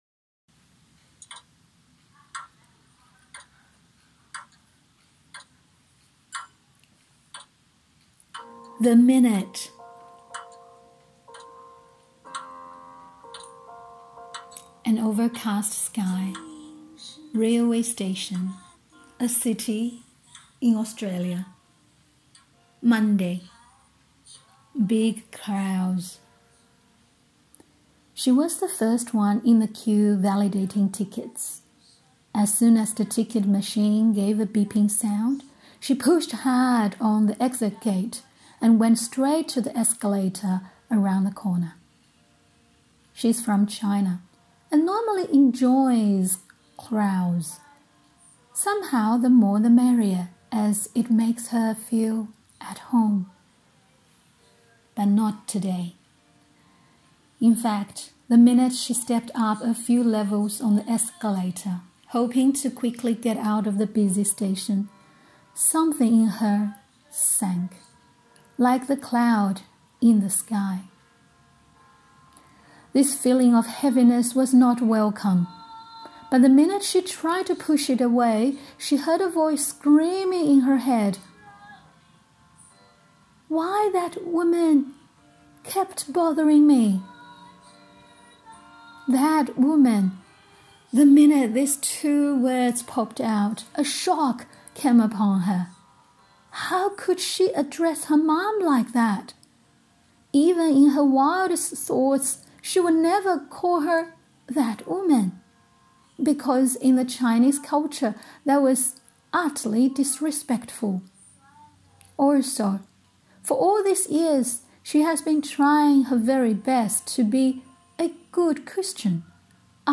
Today we bring you a very special reading from our latest anthology.
She read and recorded her story with background music that she composed.